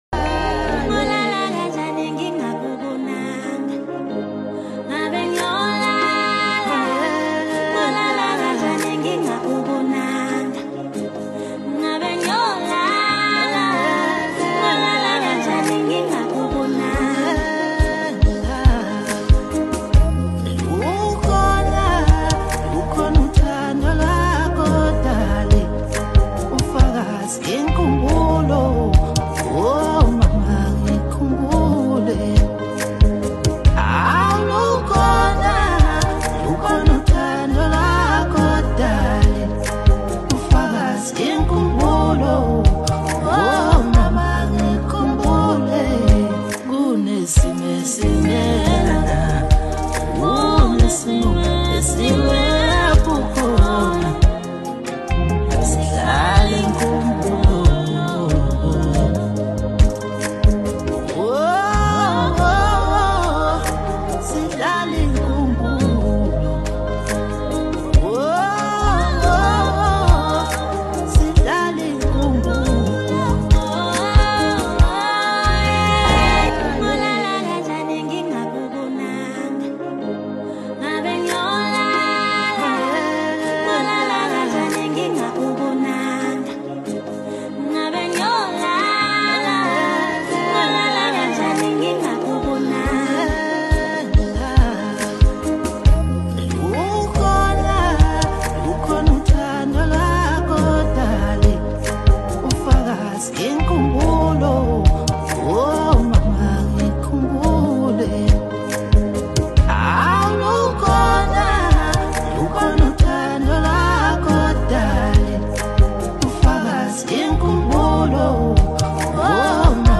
Award-winning South African music duo